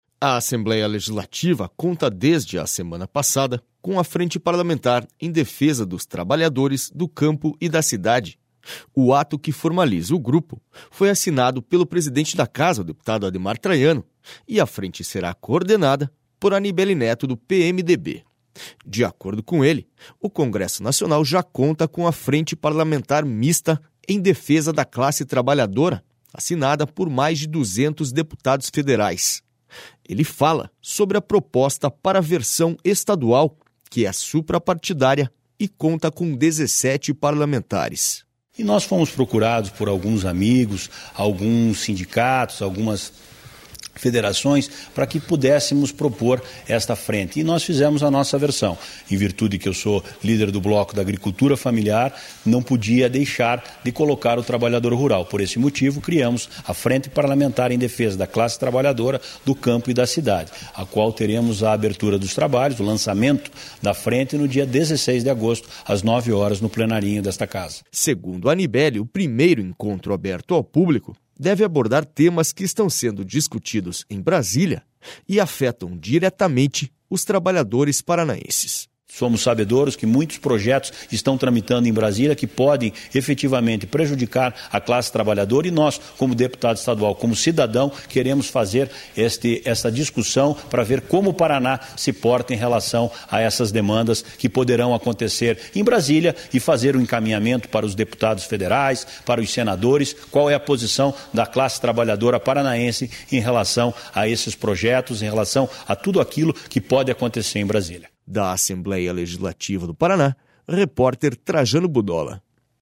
SONORA ANIBELLI NETO